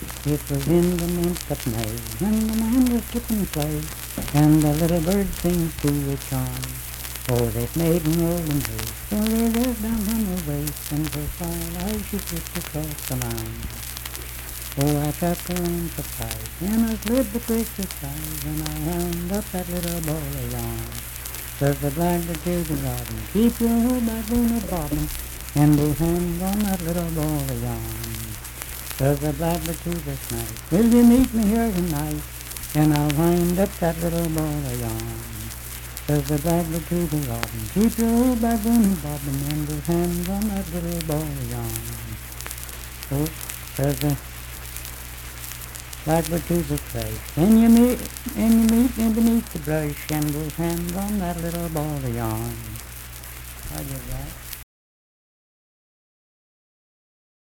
Unaccompanied vocal music performance
Bawdy Songs
Voice (sung)